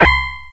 Shot2.ogg